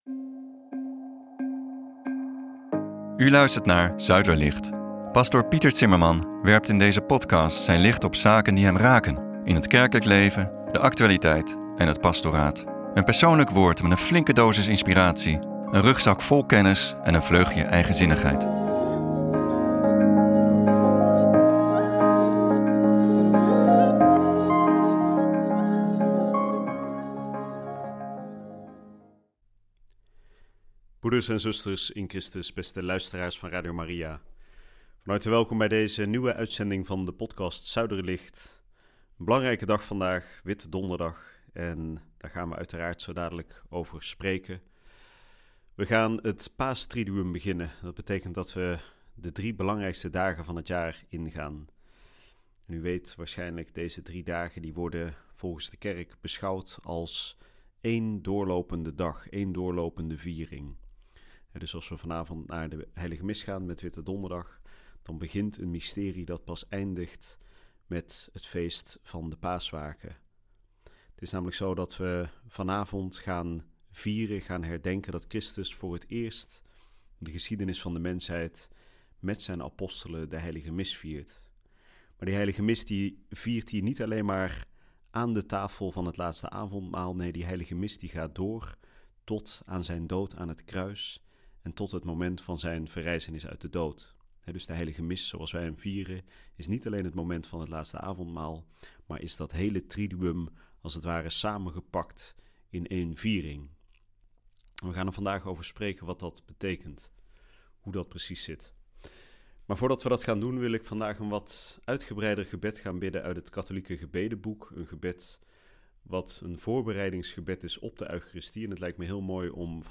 Aan het begin van de podcast wordt het gebed van de H. Ambrosius gebeden.&nbsp